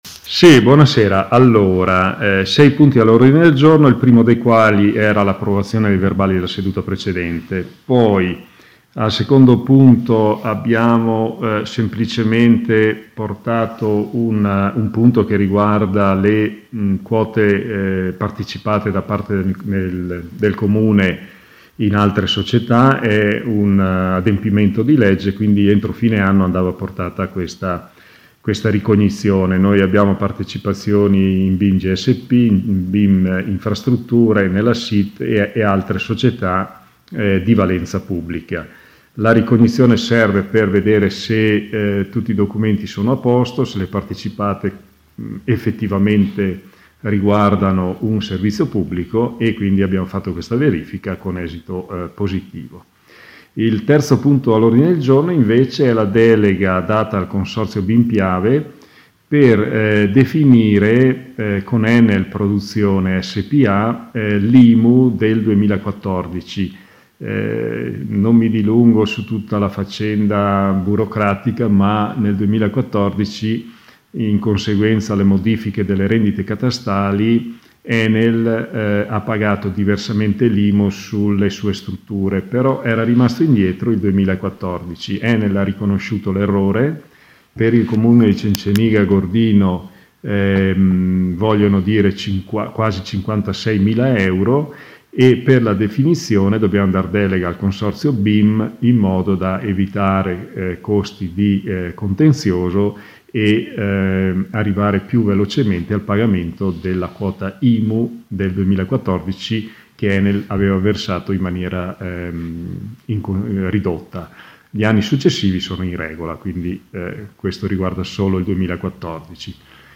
Intervento del sindaco MAURO SOPPELSA